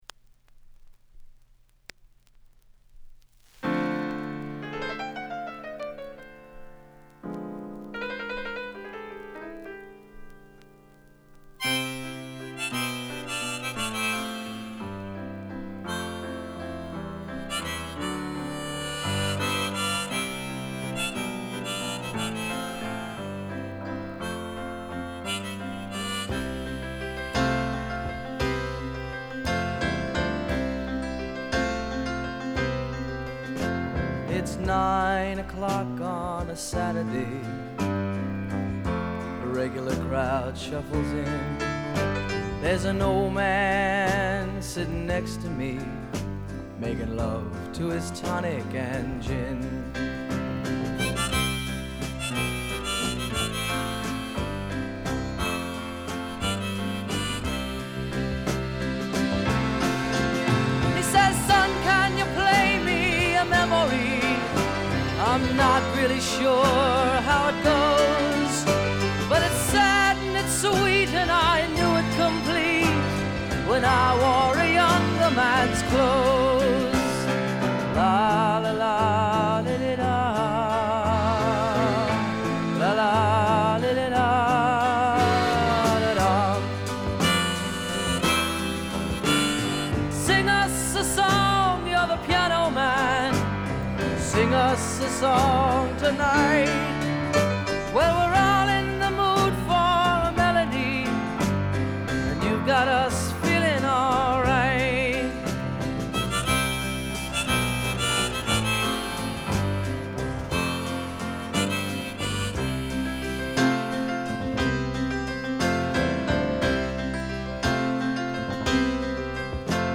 Genre: Dance / Top40 / Techno / Cheesy / Fun